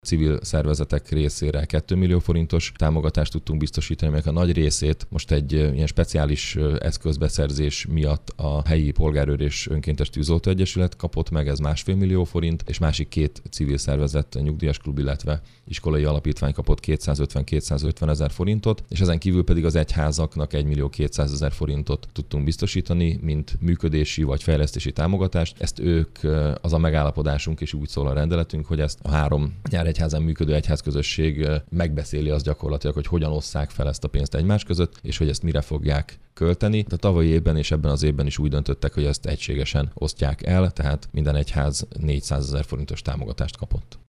A civilek esetében az önkéntes tűzoltók kapták a legnagyobb részt egy speciális eszköz beszerzésére. Mészáros Sándor polgármestert hallják.